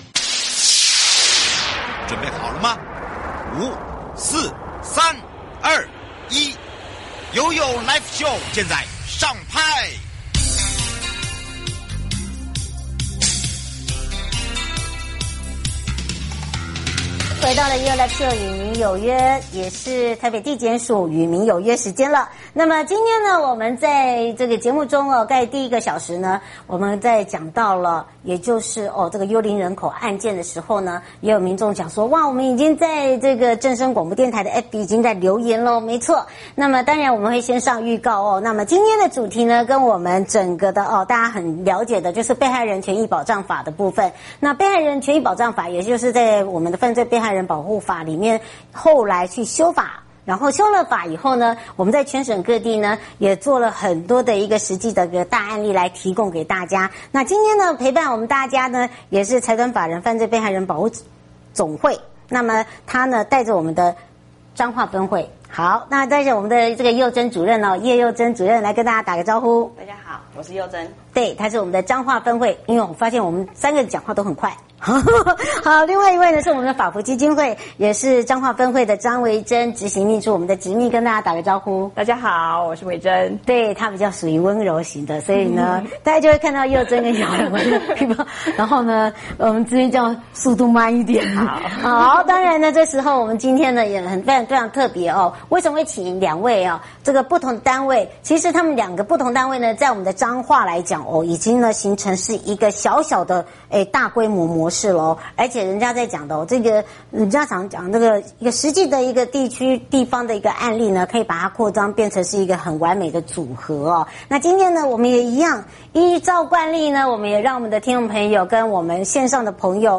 受訪者： Yoyo live show與民有約 (直播) 1.財團法人犯罪被害人保護協會總會 2.財團法人犯罪